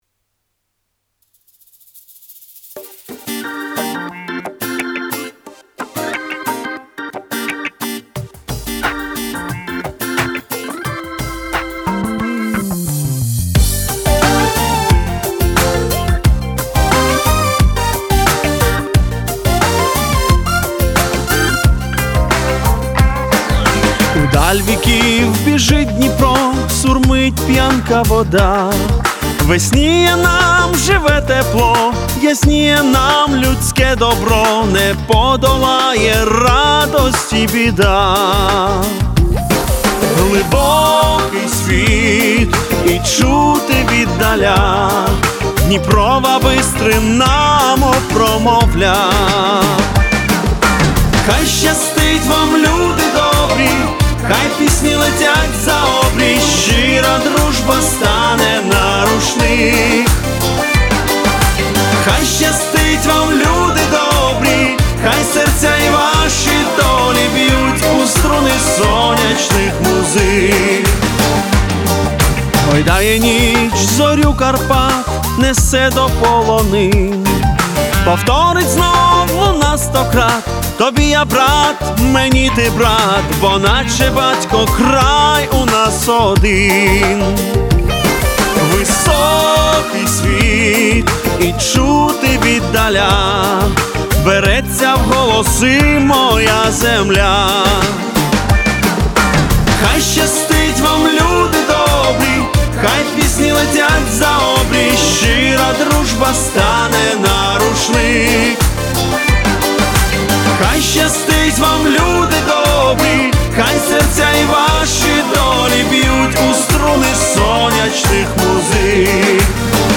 Оригінальне сучасне аранжування
Всі мінусовки жанру Народні UA
Плюсовий запис